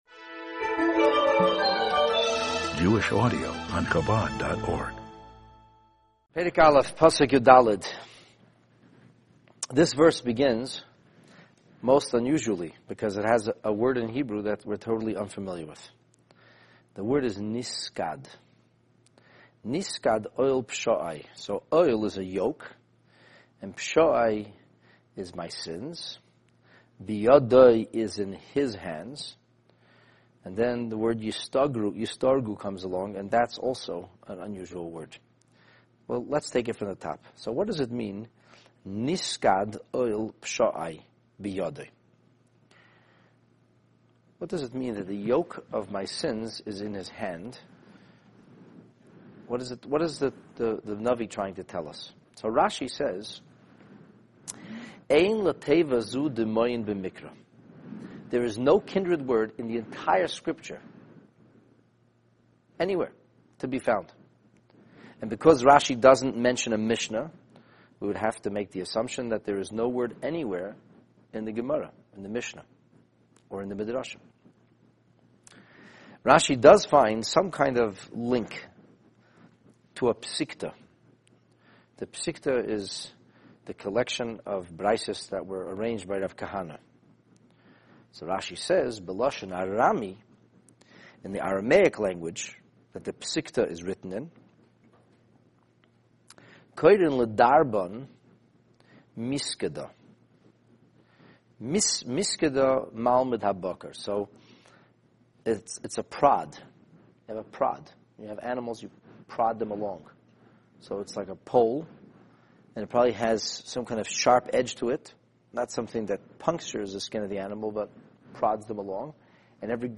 This class studies the verse (Eicha 1:14) in which Jeremiah's warnings about the devastation that would visit the Jewish people reveals a profoundly orchestrated choreography woven into the timing and magnitude of G-d's punishment. The steady snowballing effect of sin will invariably accumulate until a cascade of catastrophic consequences chokes us; yet the destruction is never complete and we always rise from the ashes.